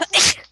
sneeze5.ogg